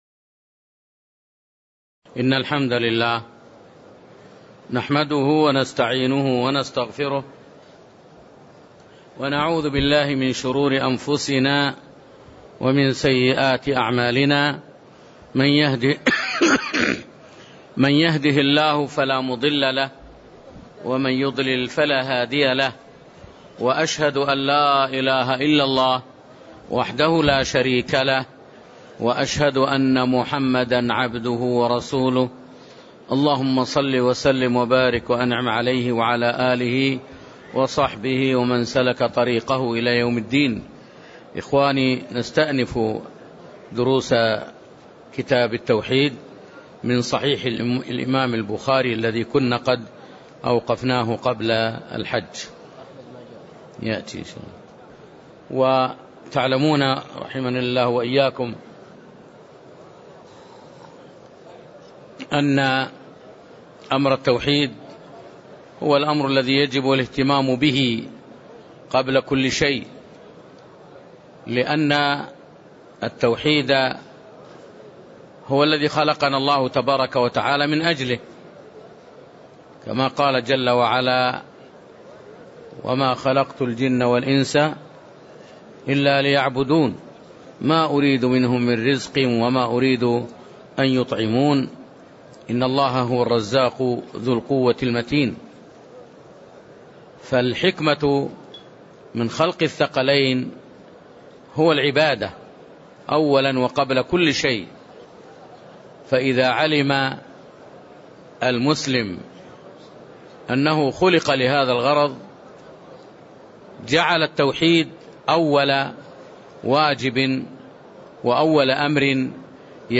تاريخ النشر ٩ محرم ١٤٣٦ هـ المكان: المسجد النبوي الشيخ